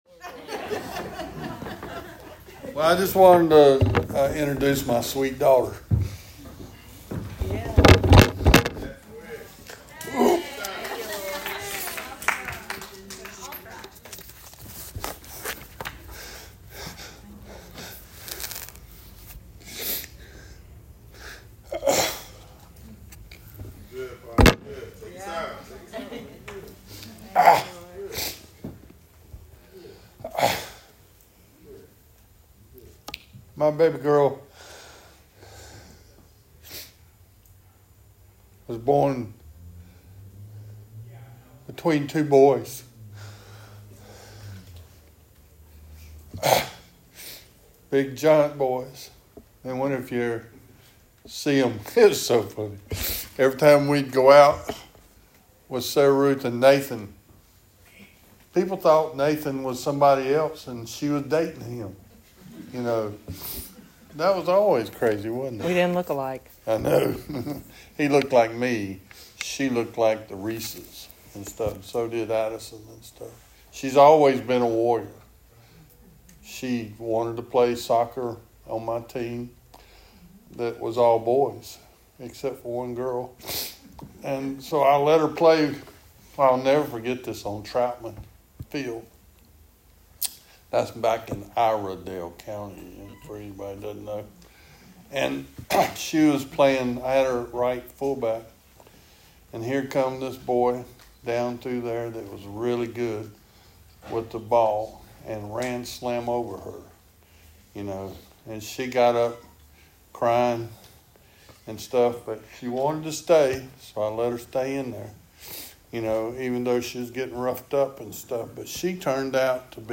Sermon of the Week: 11/5/23 – RiverLife Fellowship Church